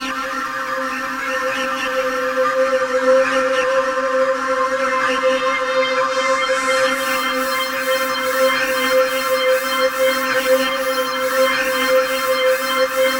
Index of /90_sSampleCDs/USB Soundscan vol.13 - Ethereal Atmosphere [AKAI] 1CD/Partition C/04-COBRA PAD